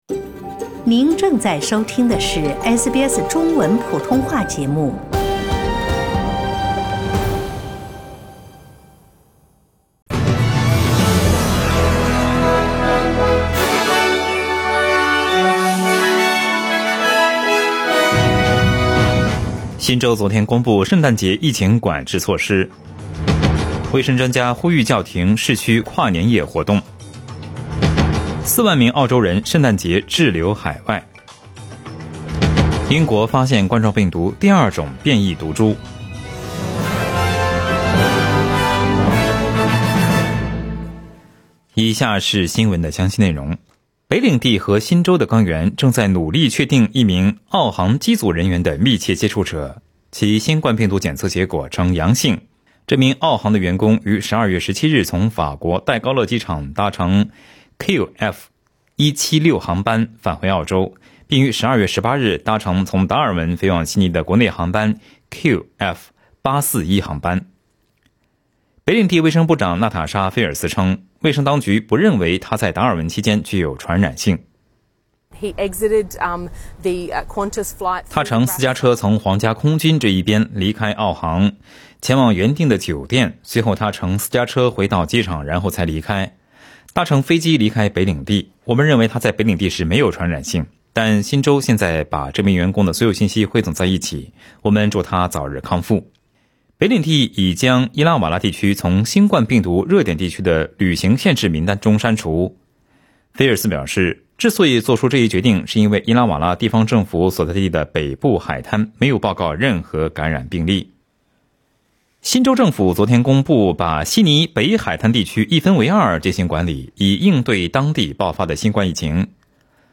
SBS早新闻（12月24日）